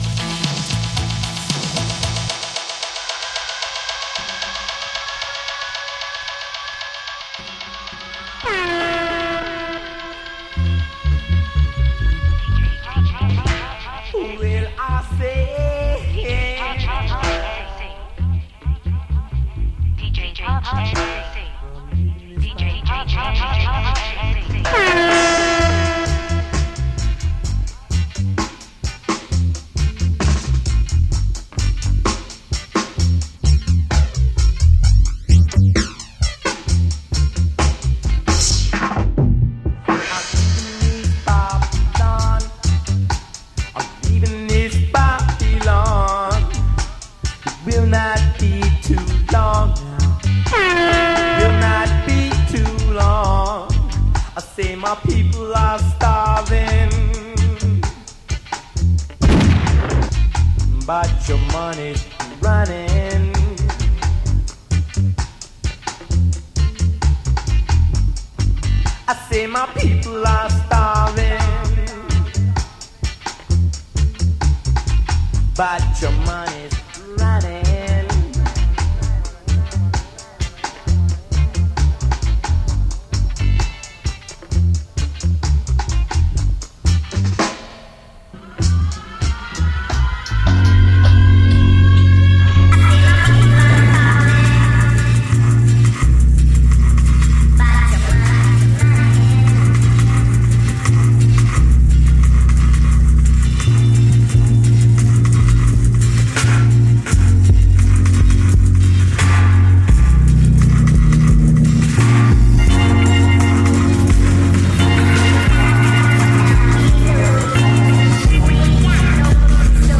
Beats Electronic